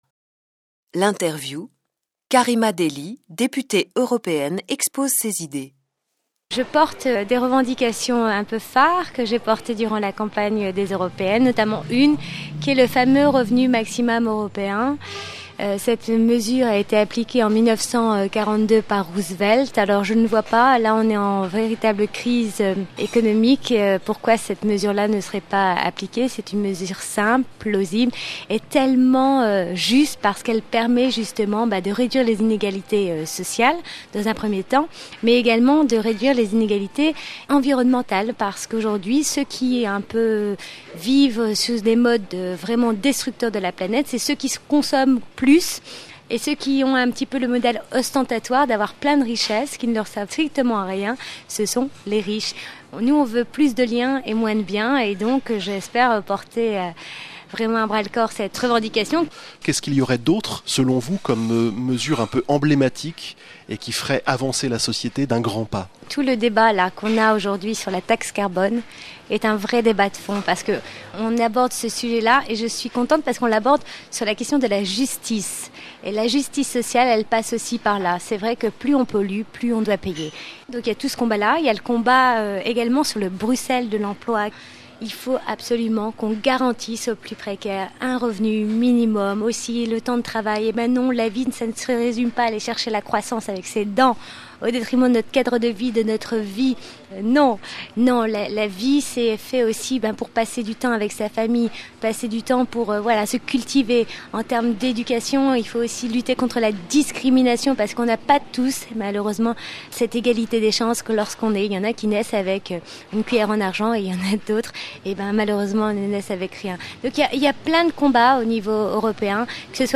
Interview.MP3